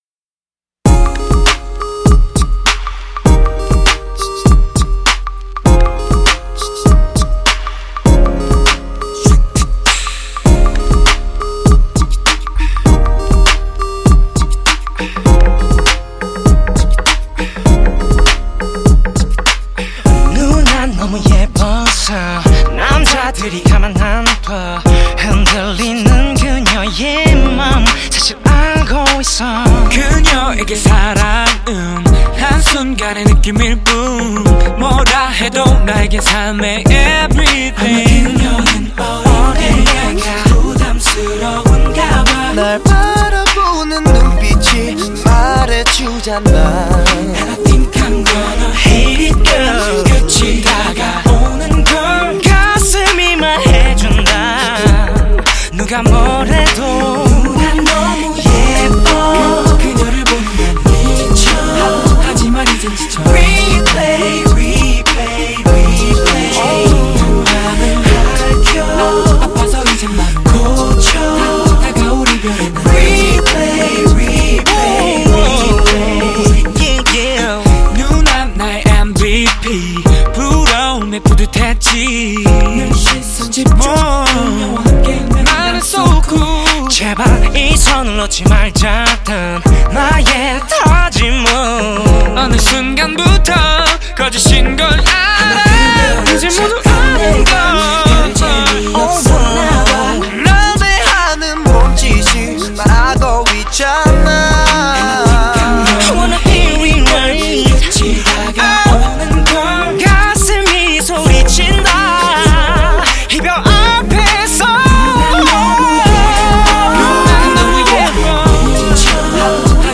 ی موزیک با هارمونی های خفن...🎻🎧🎶